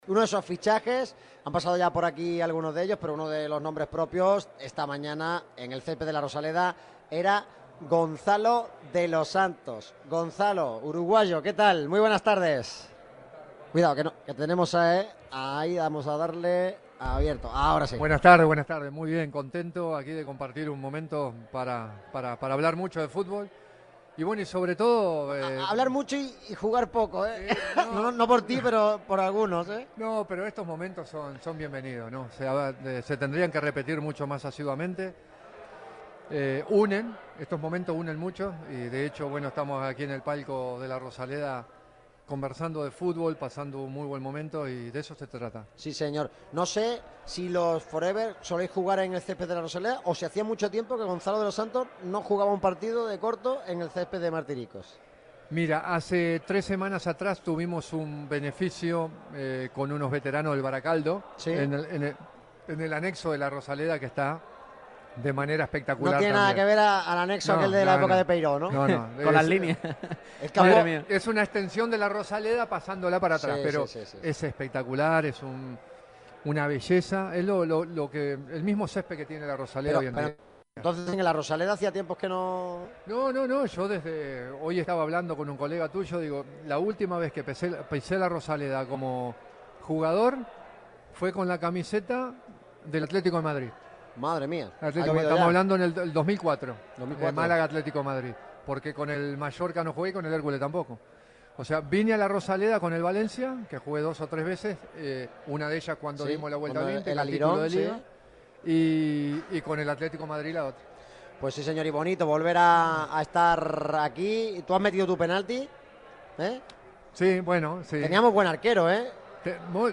Gonzalo de los Santos también fue protagonista de honor en el programa especial de Radio MARCA Málaga desde La Rosaleda con motivo del I Torneo Jorge Ramos.